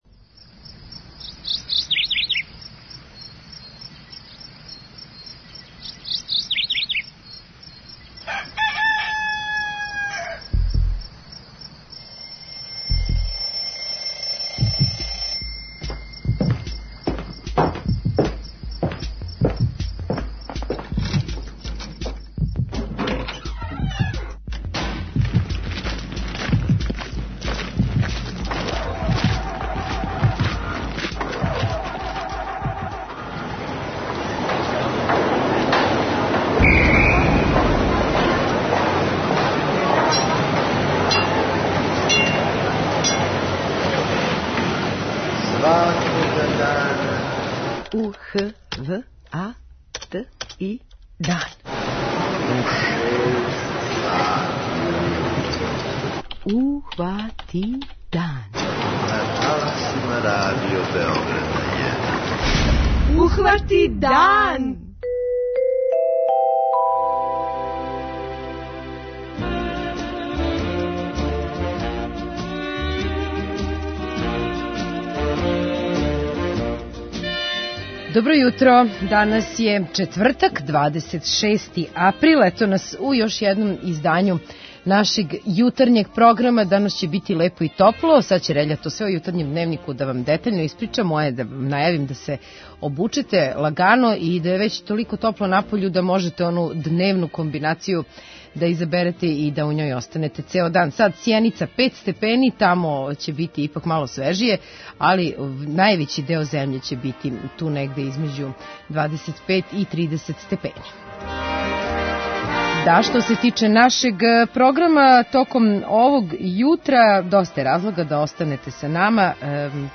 Чућемо младе боксере који се тренутно припремају за предстојеће Европско првенство у Бугарској.